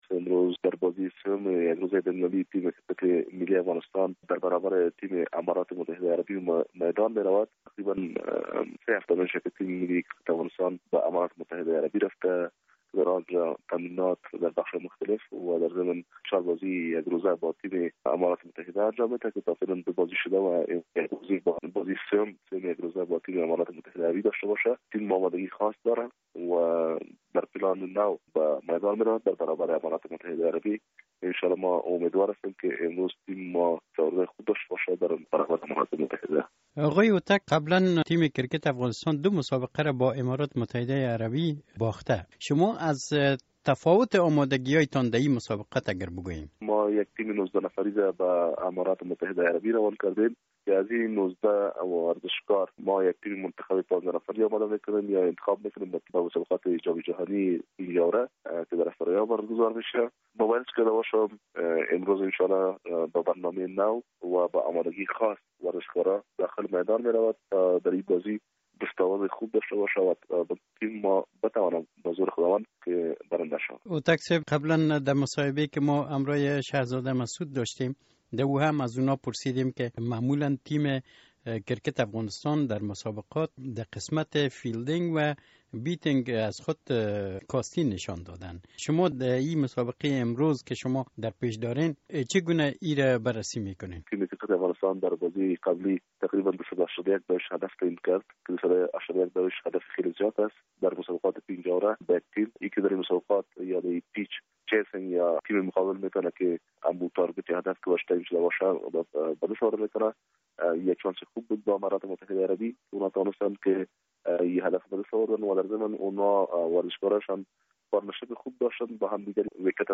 مصاحبه در مورد مسابقه کرکت بین افغانستان و امارات متحده